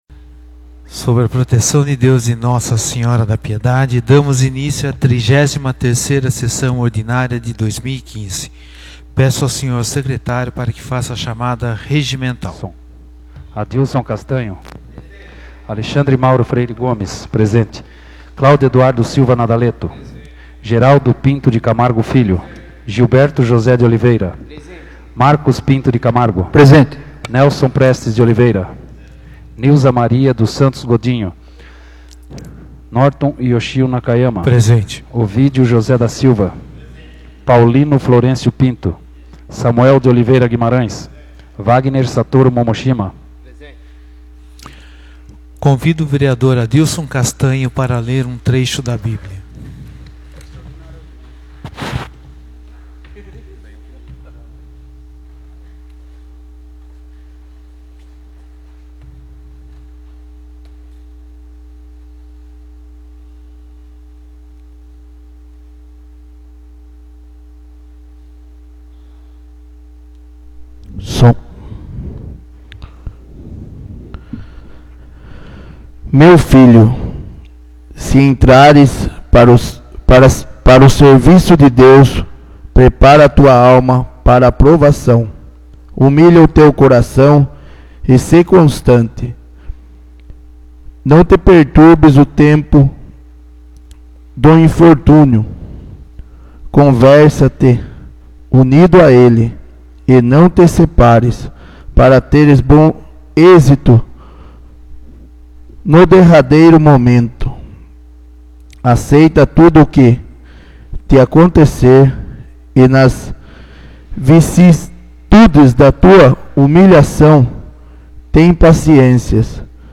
33ª Sessão Ordinária de 2015